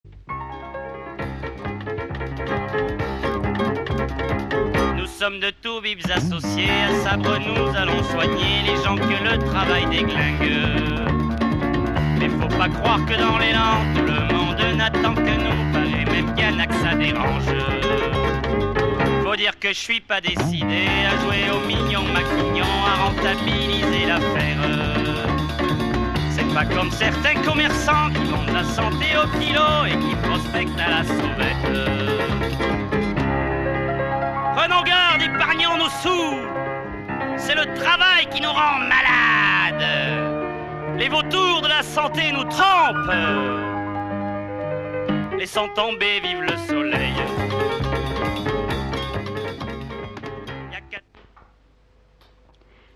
• Musik